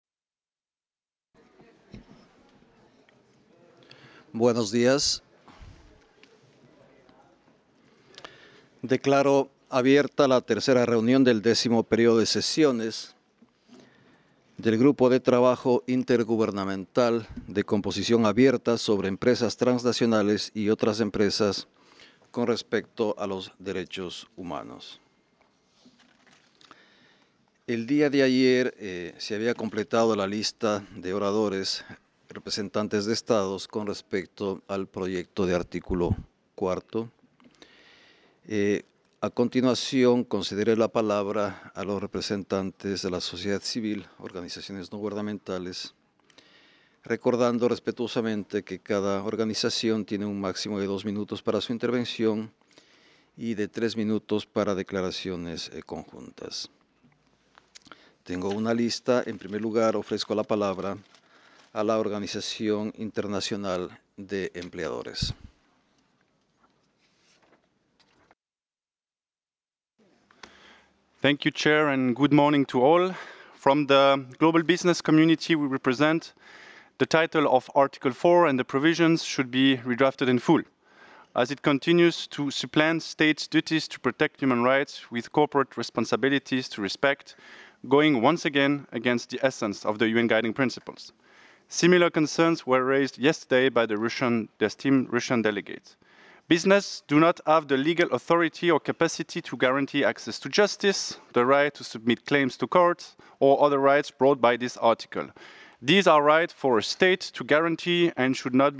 December 17, 2024 10:07 Room XVII